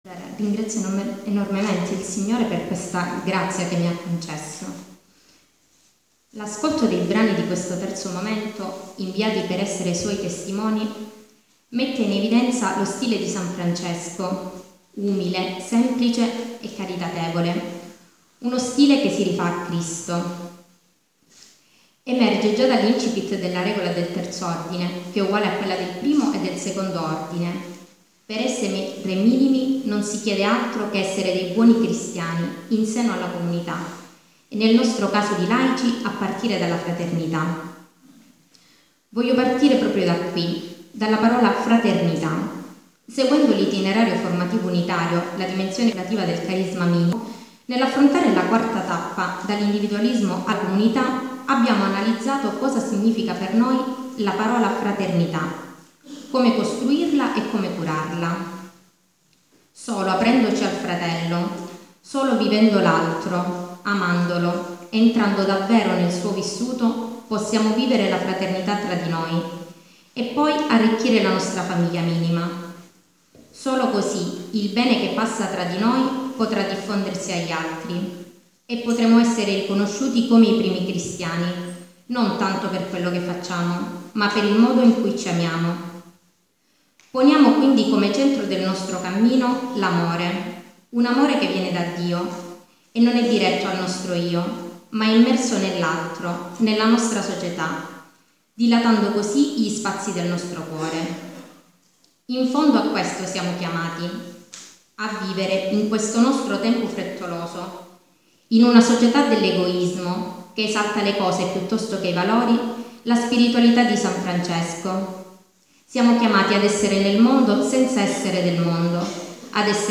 Hanno trasmesso con il loro linguaggio semplice e diretto la loro visione sull’essere santi oggi e santi Minimi.